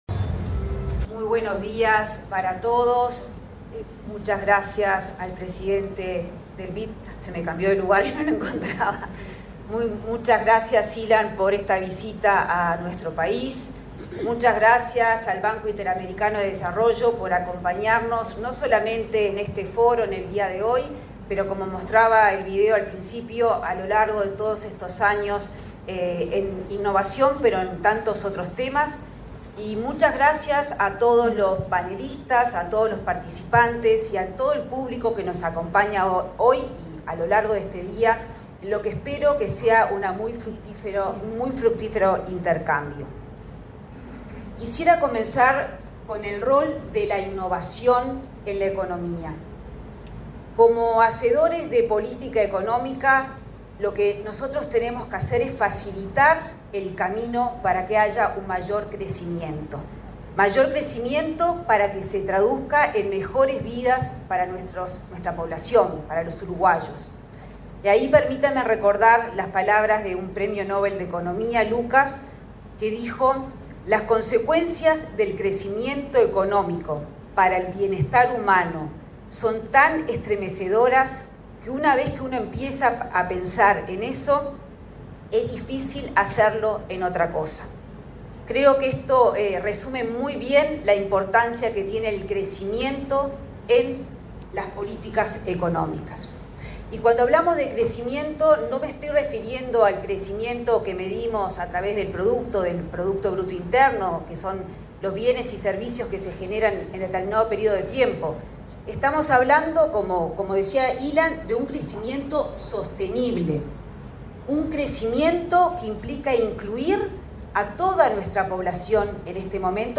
Palabras de la ministra de Economía, Azucena Arbeleche
Palabras de la ministra de Economía, Azucena Arbeleche 29/08/2024 Compartir Facebook X Copiar enlace WhatsApp LinkedIn Este jueves 29, la ministra de Economía, Azucena Arbeleche, disertó en el Foro de Innovación e Inteligencia Artificial de Uruguay: El Camino hacia un Hub de Innovación Global. El evento se realizó en el Laboratorio Tecnológico del Uruguay.